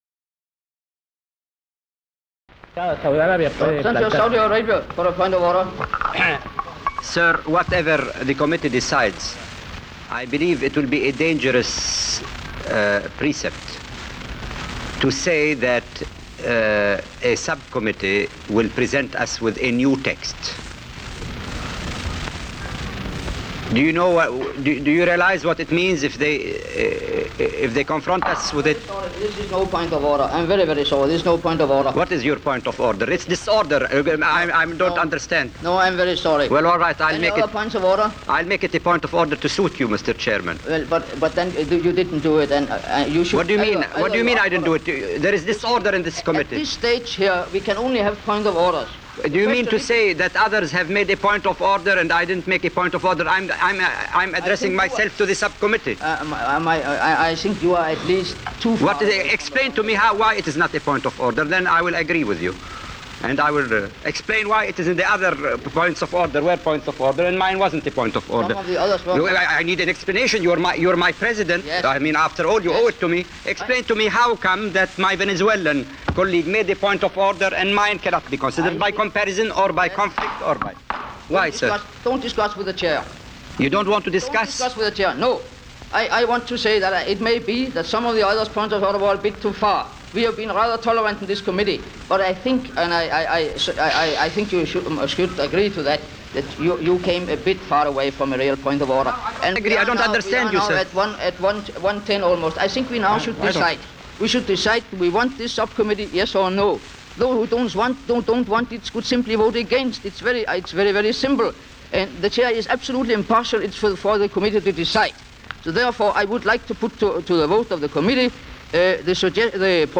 Unidentified United Nations delegate argues with the unidentified Chairman of Committee